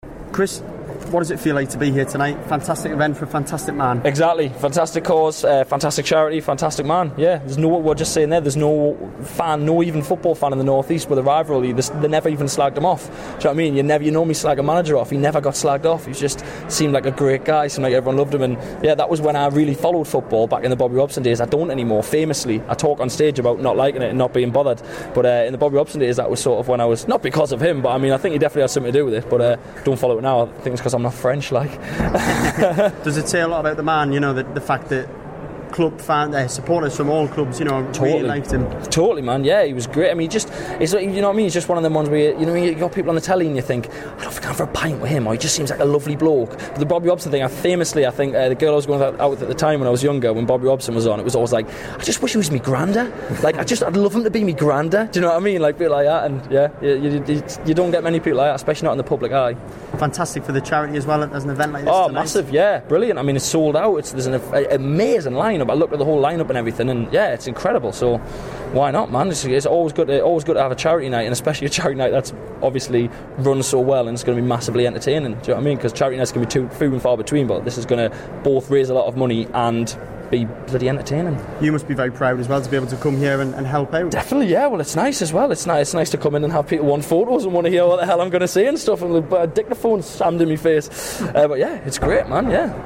My chat with the TV man for Metro Radio News